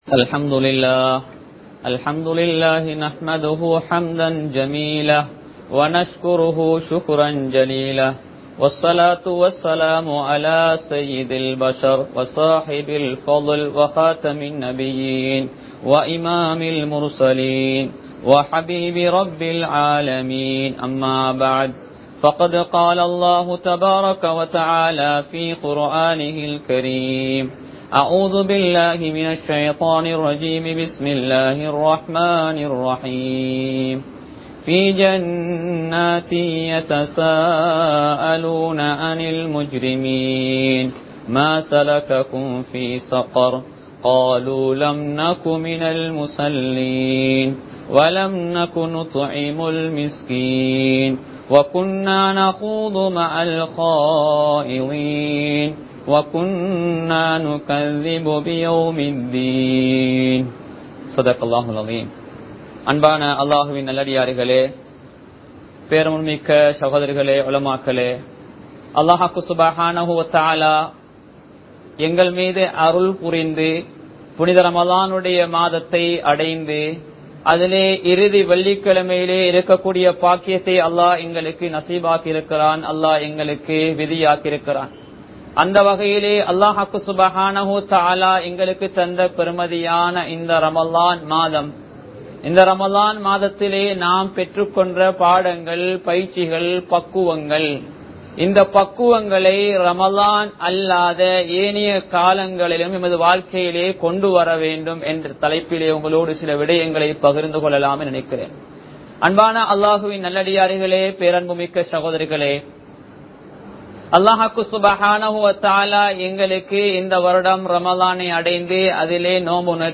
அமல்களைத் தொடர்வோம் | Audio Bayans | All Ceylon Muslim Youth Community | Addalaichenai
Kollupitty Jumua Masjith